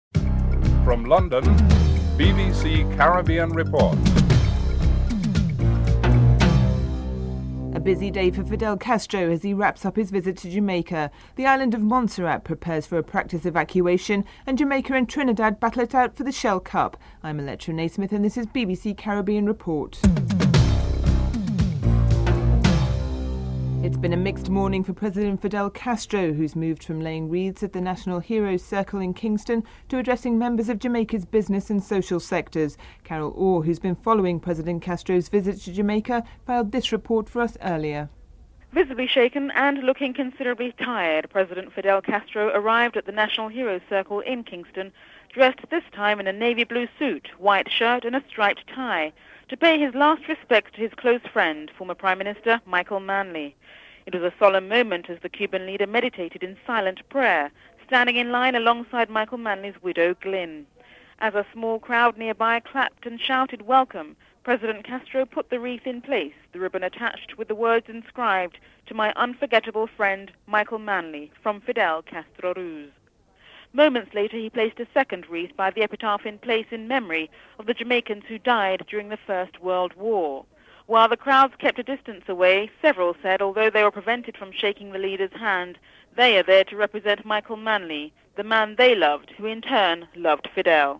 4. The island of Montserrat prepares for a practice evacuation. Montserrat's Chief of Staff Claude Hogan is interviewed (09:21-11:24)